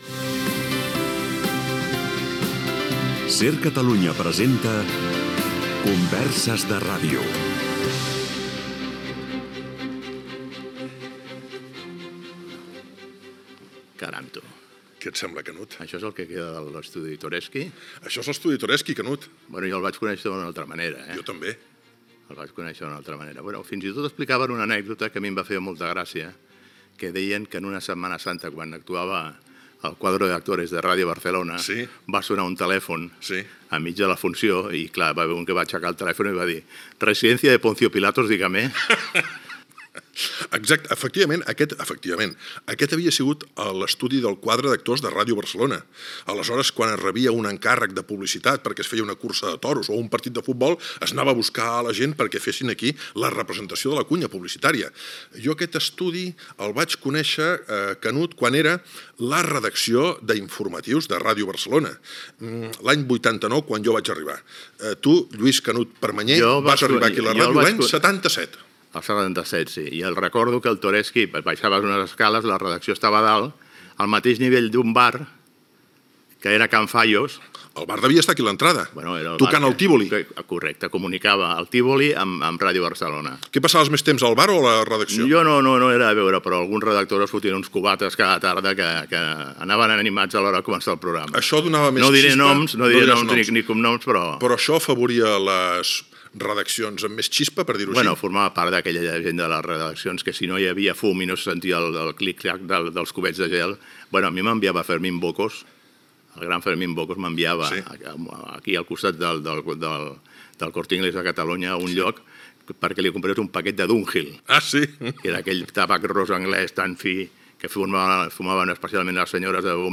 Careta del programa, entrevista al periodista esportiu
Entreteniment